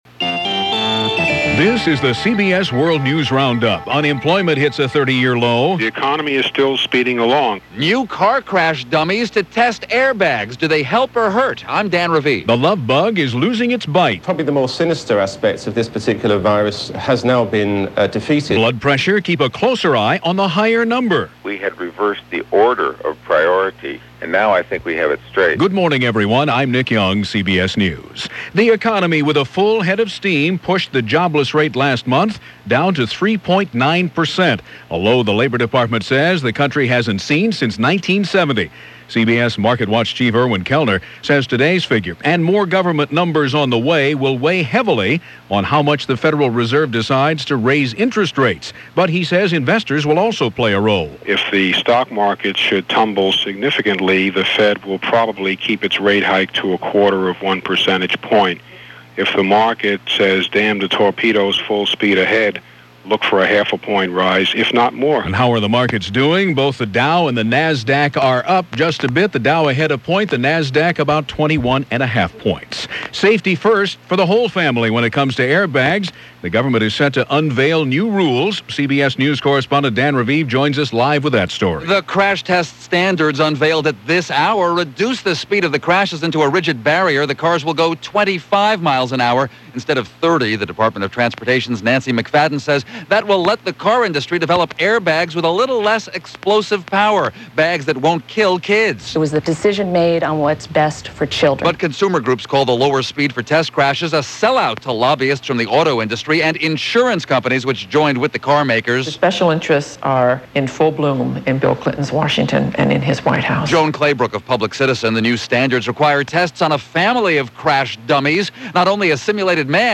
That’s just a slice of what went on, this otherwise normal May 5th in 2000 as presented by The CBS World News Roundup.